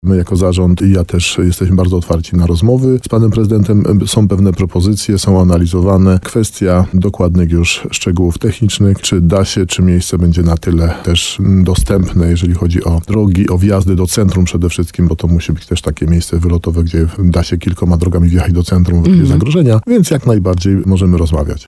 Ze wstępnych informacji wynika, że teren, który zaproponowało miasto może nie nadawać się pod zabudowę – mówił na antenie RDN Nowy Sącz starosta nowosądecki Tadeusz Zaremba. Gość programu Słowo za Słowo podkreślał, że służby powiatowe analizują jeszcze propozycję władz miasta.